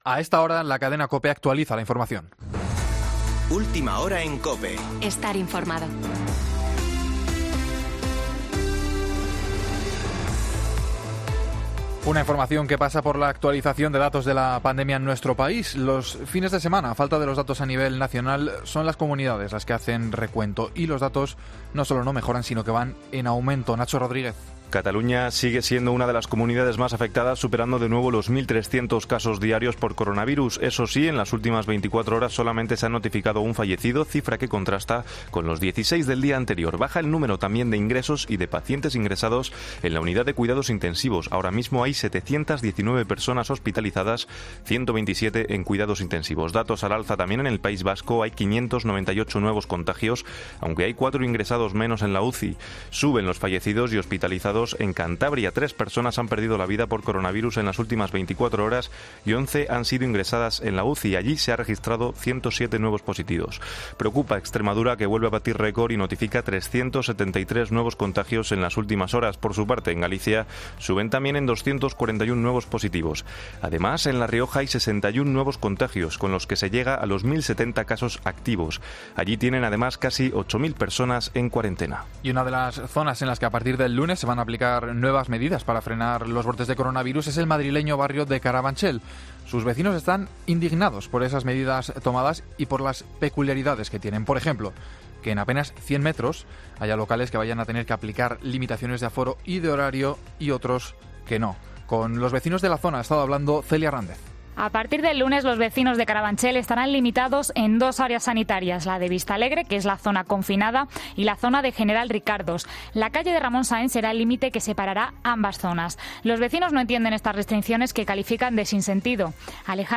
Boletín de noticias de COPE del 19 de septiembre de 2020 a las 18.00 horas